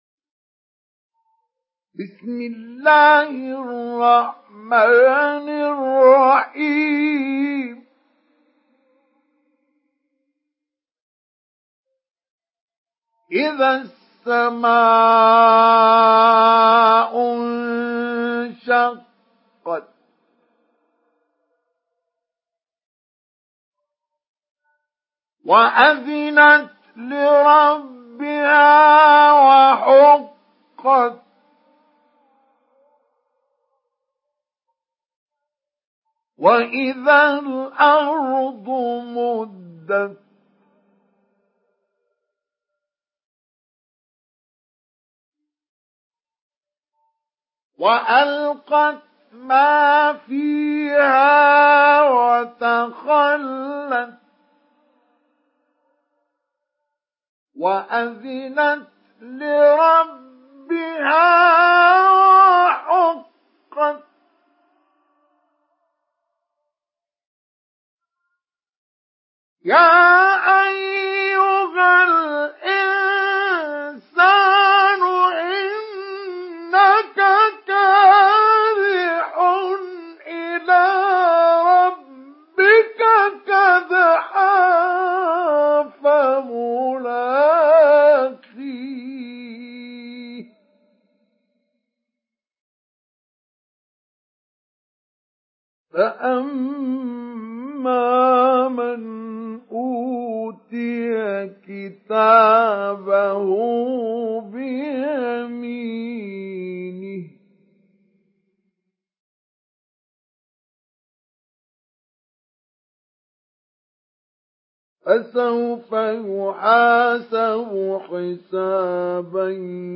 Surah الانشقاق MP3 in the Voice of مصطفى إسماعيل مجود in حفص Narration
Surah الانشقاق MP3 by مصطفى إسماعيل مجود in حفص عن عاصم narration.